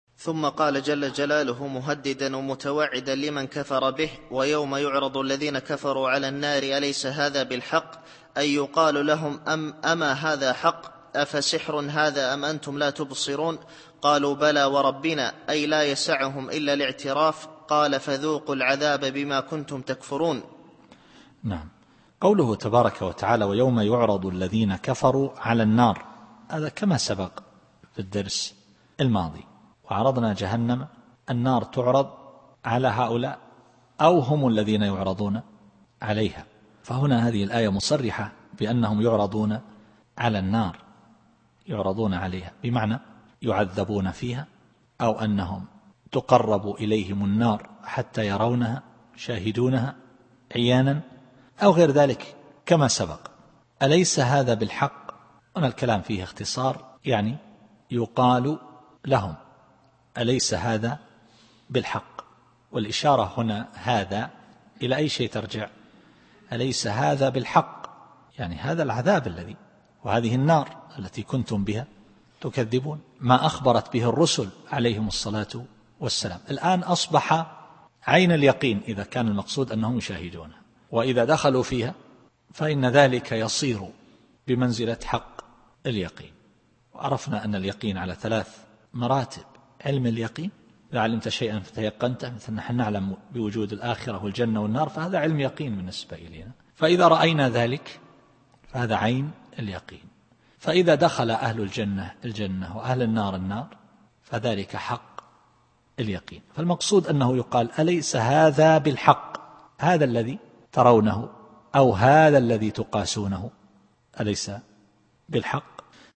التفسير الصوتي [الأحقاف / 34]